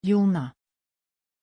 Pronunciation of Jonah
pronunciation-jonah-sv.mp3